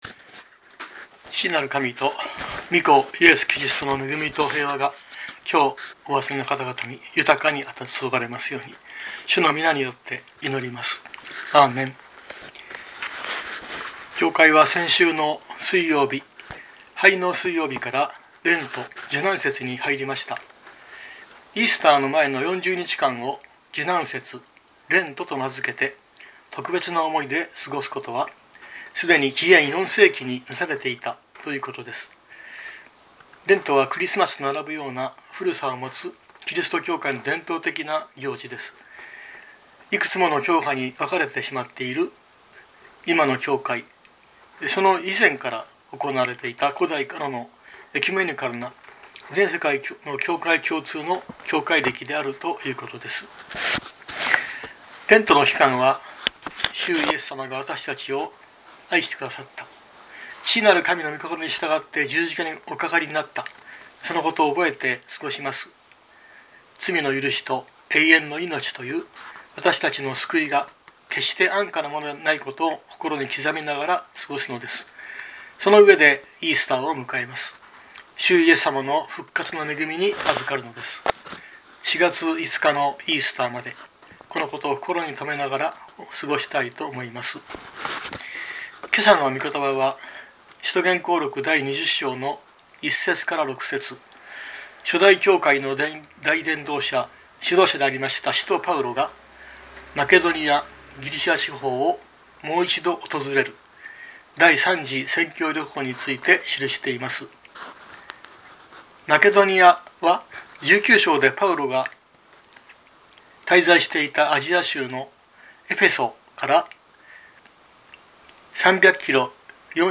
日曜朝の礼拝